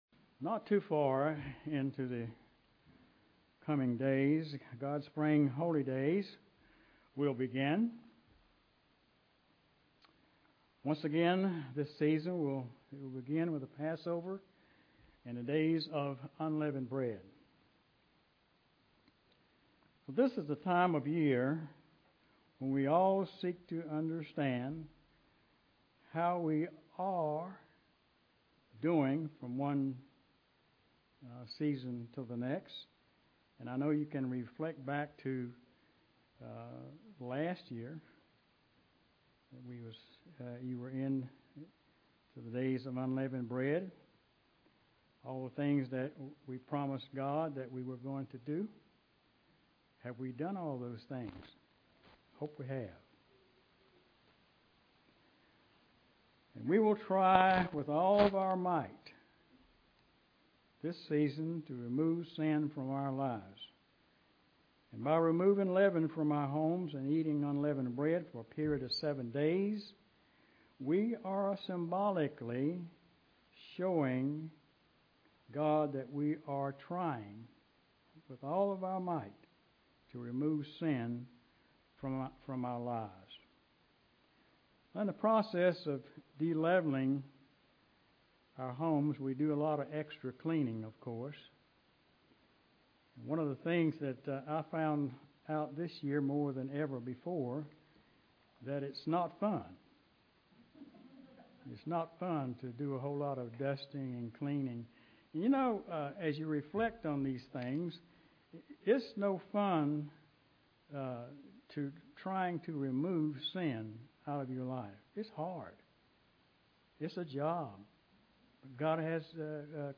Given in Greensboro, NC